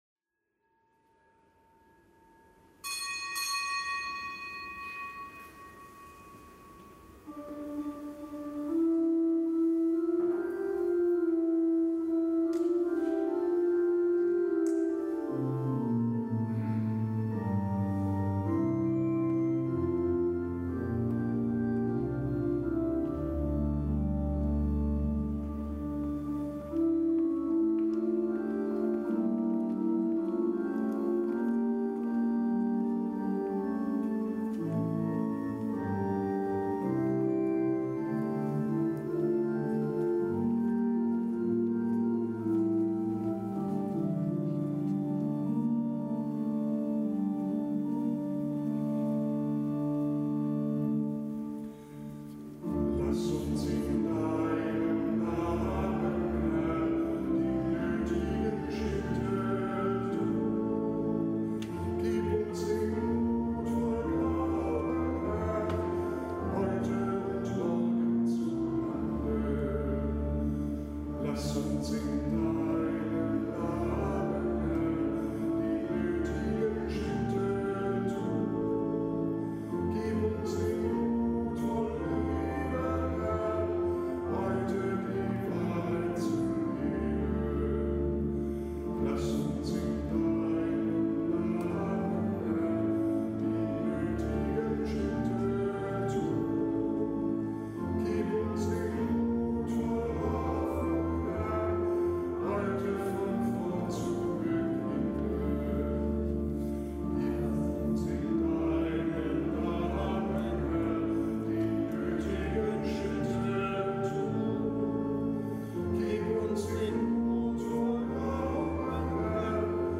Kapitelsmesse aus dem Kölner Dom am Montag der siebenundzwanzigste Woche im Jahreskreis, Nichtgebotener Gedenktag Hl. Bruno, Mönch, Einsiedler, Ordensgründer (RK; GK).